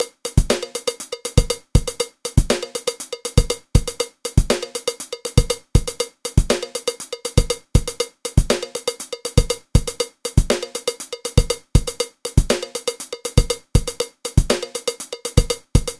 Voici deux exemples, inspirés de vidéos de Jimmy Branly, de rythmes qui peuvent être joués à la batterie et aux timbales :
Timba (timbales)
Le rythme de cloche est joué avec la main droite dans le premier exemple et avec la main gauche dans le second.
timba_timbales2.mp3